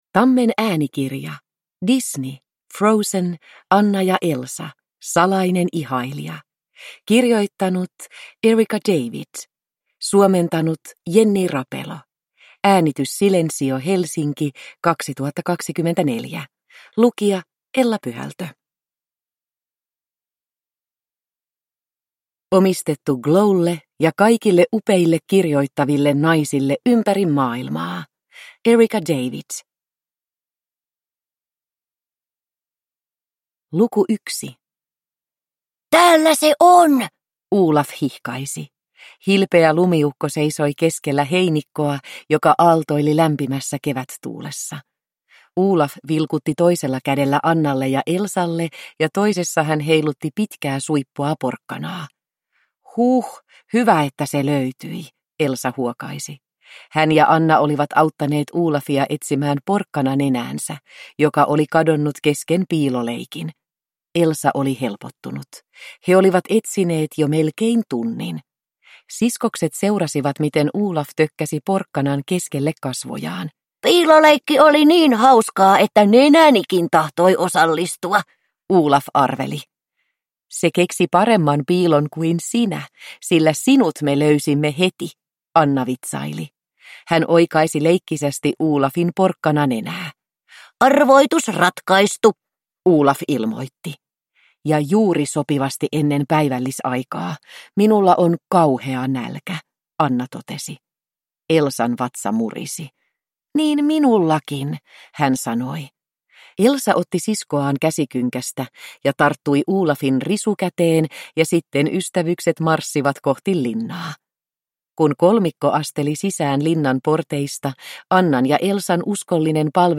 Frozen. Anna & Elsa. Salainen ihailija – Ljudbok